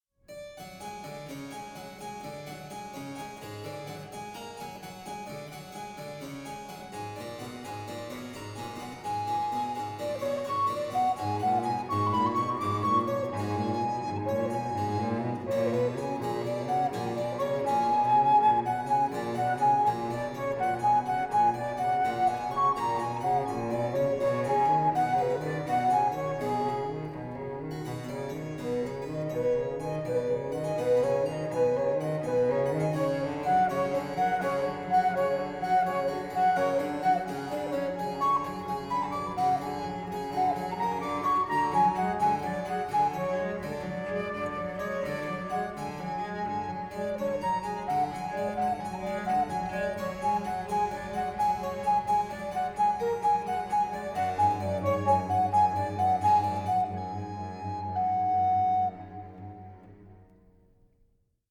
Stereo
baroque flute, alto recorder, baroque cello and harpsichord